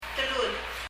btelul　　　　[(p)tɛlul]　　　頭　　　　head
聞き取りにくいですが、先頭の b は「子音の前後の b は
[p]音で」　というルールで、[p]音になっている(はず）ですが、
聞き取れません。